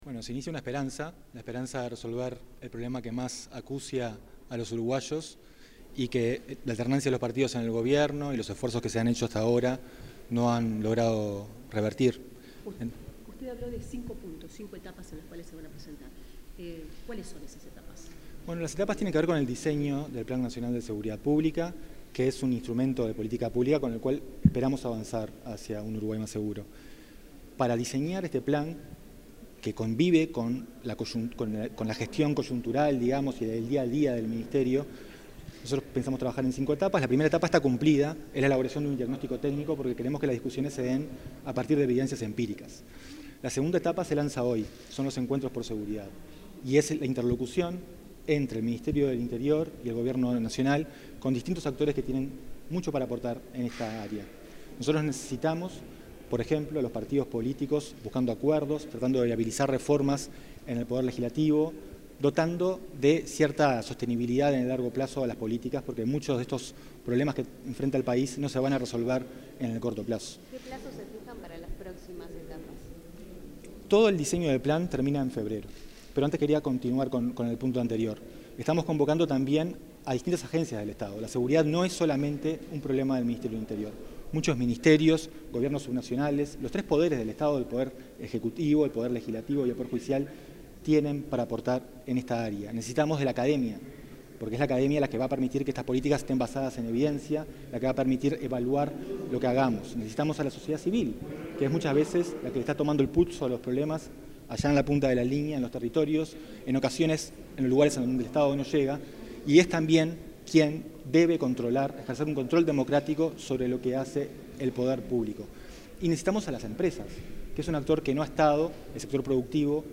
Declaraciones del asesor en seguridad pública Emiliano Rojido
Declaraciones del asesor en seguridad pública Emiliano Rojido 28/07/2025 Compartir Facebook X Copiar enlace WhatsApp LinkedIn En el lanzamiento de los Encuentros por Seguridad, que se desarrolló en la Torre Ejecutiva, el asesor en seguridad pública, monitoreo de información y sistema penitenciario Emiliano Rojido dialogó con los medios de prensa.